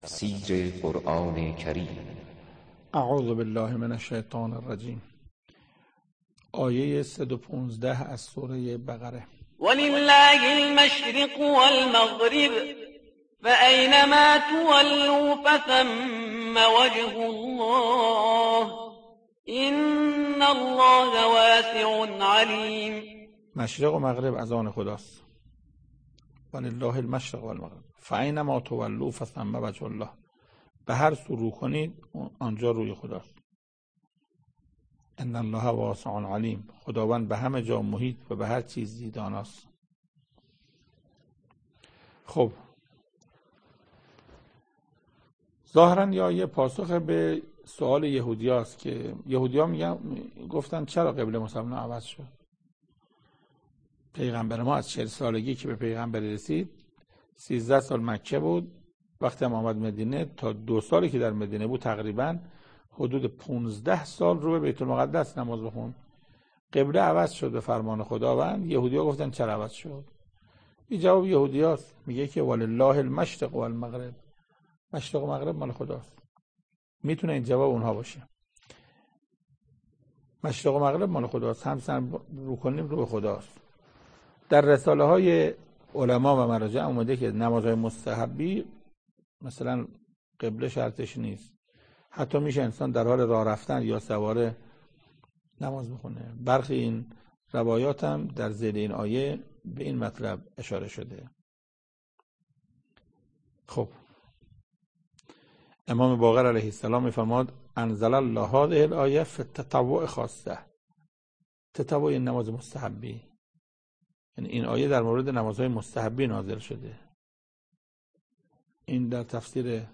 تفسیر صد و پانزدهمین آیه از سوره مبارکه بقره توسط حجت الاسلام استاد محسن قرائتی به مدت 3 دقیقه